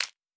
Fantasy Interface Sounds
UI Tight 23.wav